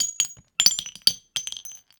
weapon_ammo_drop_15.wav